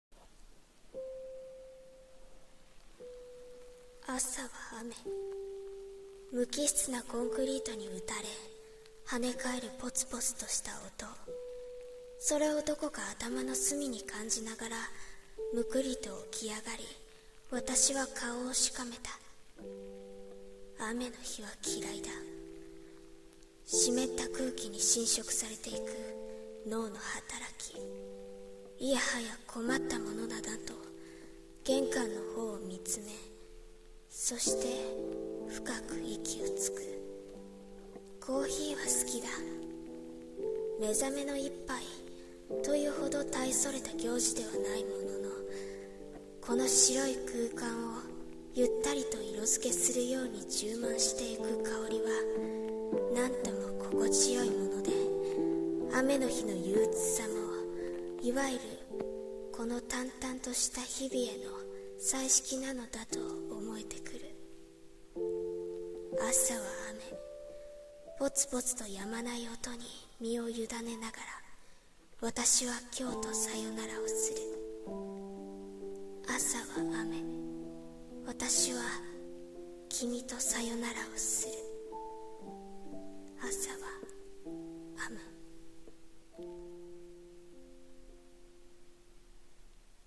【朗読台本】「雨」【コラボ用声劇】